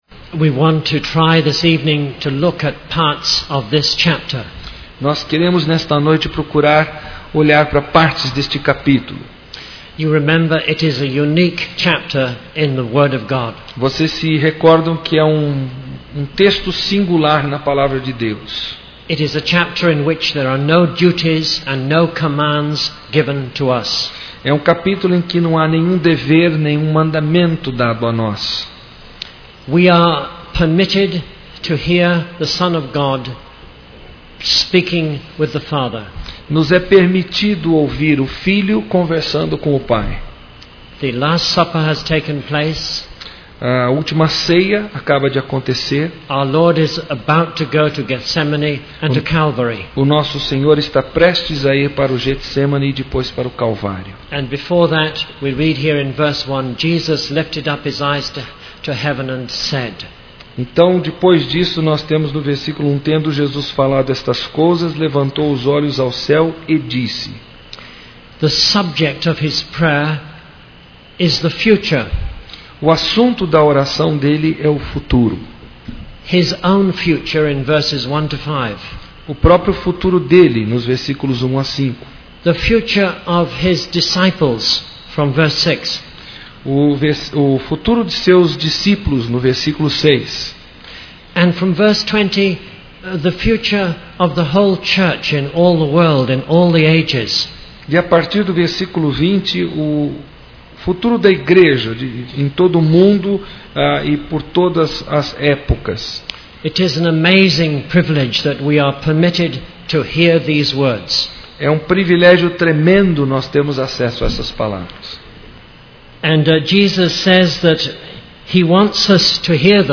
Conferência: 16ª Conferência Fiel para Pastores e Líderes Tema: O Firme Alicerce do Aconselhamento e